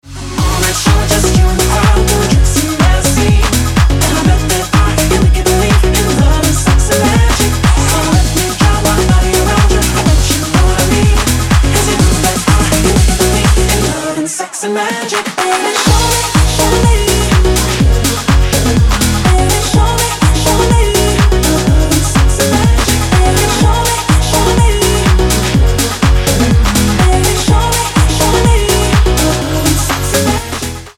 • Качество: 320, Stereo
зажигательные
EDM
Dance Pop
пианино
красивый женский голос
динамичные
Динамичный танцевальный рингтон